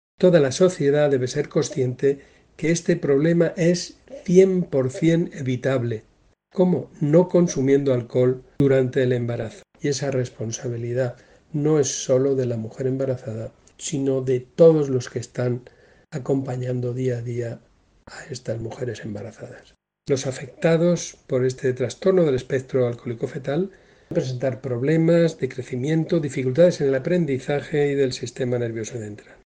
Declaraciones del director general de Salud Pública y Adicciones, José Jesús Guillén, sobre el Trastorno del Espectro Alcohólico Fetal